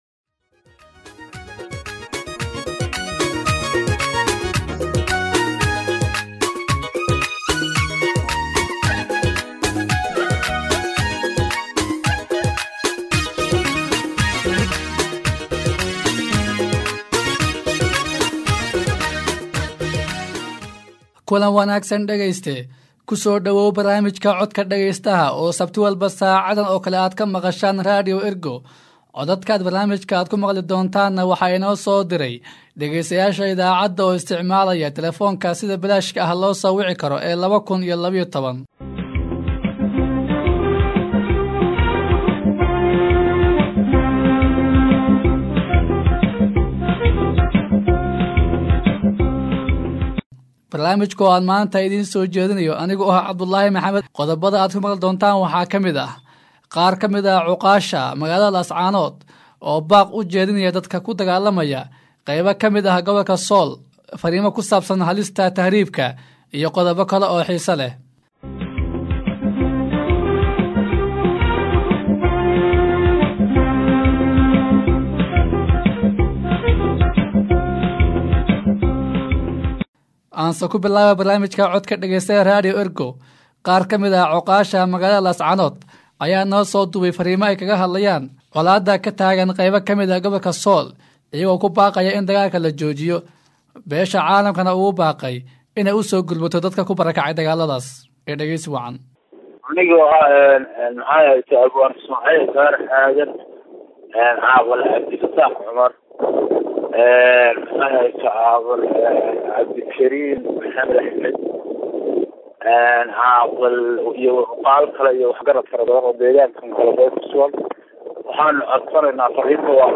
Fikradaha dadka dhegeystayaasha ah ee jooga gudaha Soomaaliya ay kaga soo dhiibtaan barnaamijyada idaacadda Ergo, kuna soo diraan lambarka gaaban ee 2012.
Haddii aad joogto Soomaaliya oo aad jeceshahay in aad barnaamijka ka qeyb qaadato si bilaash ah ayaad farriintaada oo cod ah noogu soo duubi kartaa.